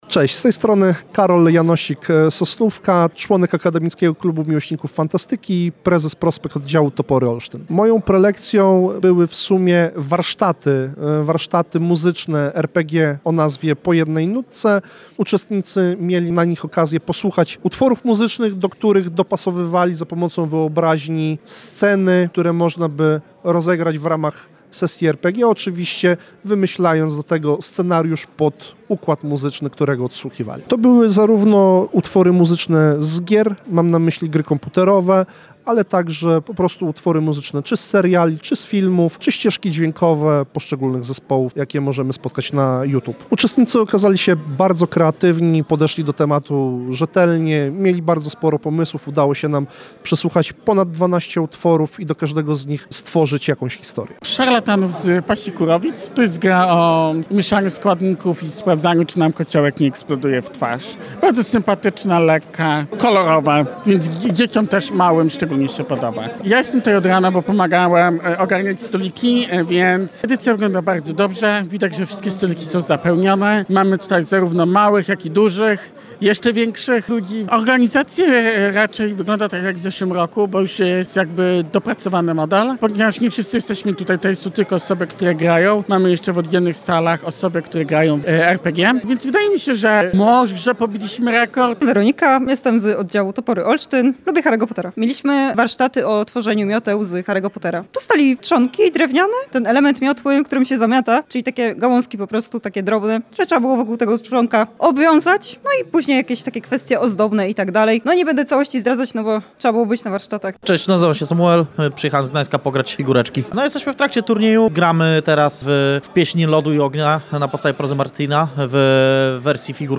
Nasz reporter rozmawiał także z uczestnikami kolejnego Gamegrindera i autorami prelekcji.